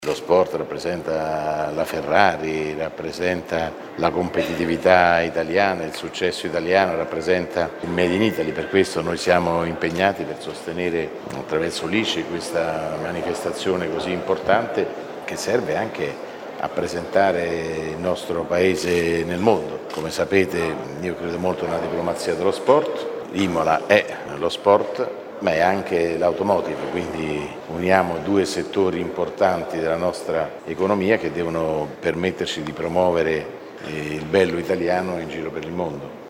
Il Ministro Antonio Tajani al microfono